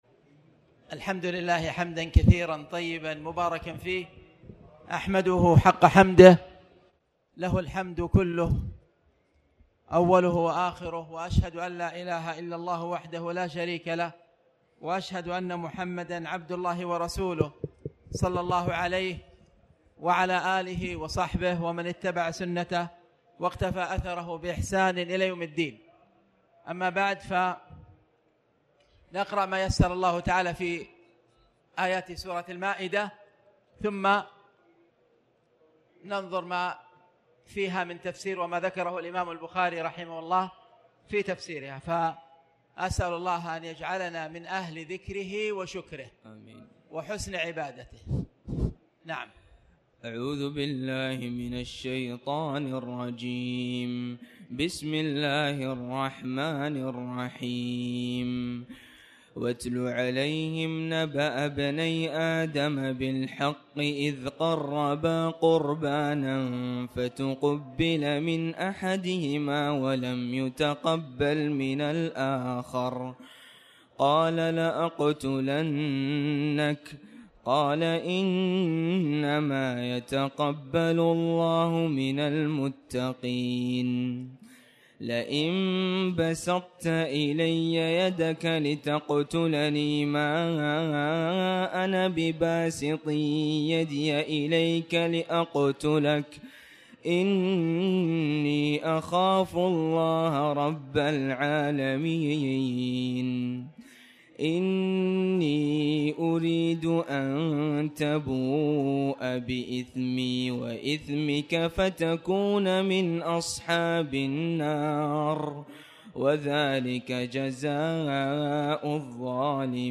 تاريخ النشر ١٨ رمضان ١٤٣٩ هـ المكان: المسجد الحرام الشيخ